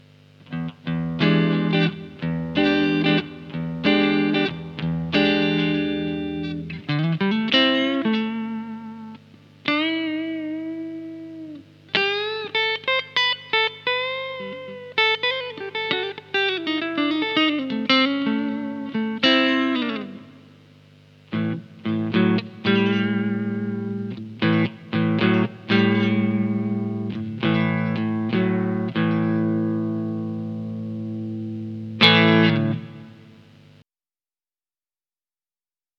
Bon allez, pour terminer en beauté, voici les samples en clean.
Le preamp 2 est le studio preamp de mesa
ComparatifPreamp-Clean-Preamp2.mp3